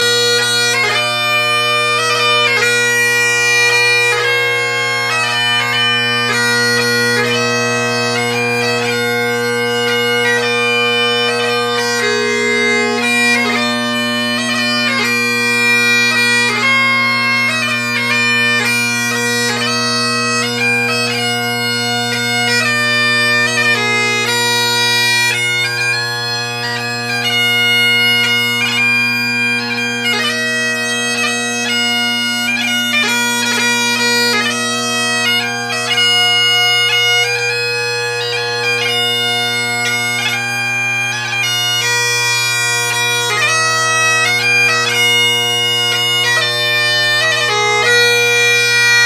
Great Highland Bagpipe Solo
Tuning, or the playing, isn’t great or perfect, but good enough for 20 minutes out of the shipping box!
Pipes with Ezee drone reeds: